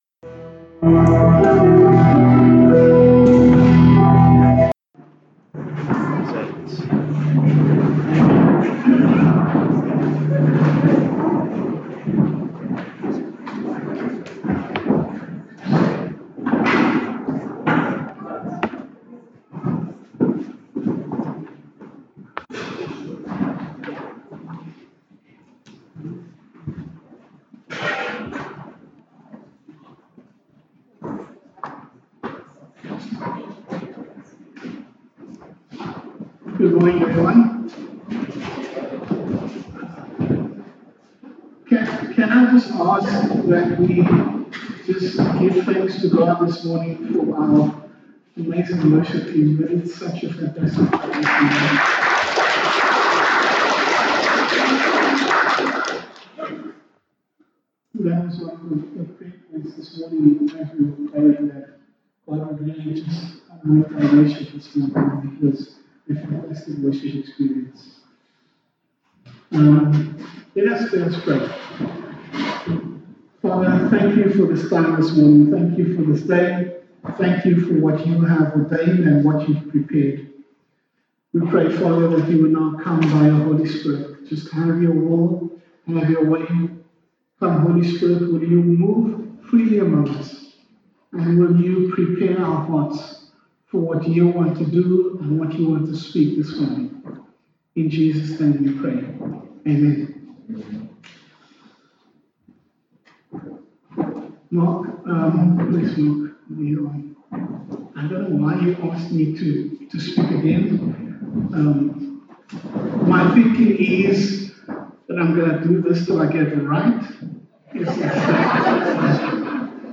Sunday Service – 30 April
Sermons